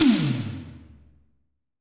Tom_A1.wav